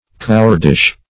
Search Result for " cowardish" : The Collaborative International Dictionary of English v.0.48: Cowardish \Cow"ard*ish\, a. Cowardly.